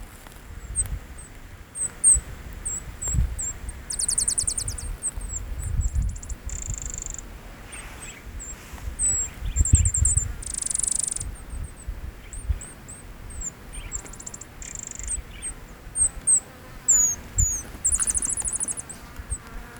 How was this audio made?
Mount Ive, Mount Ive Station, Gawler Ranges, South Australia, Australia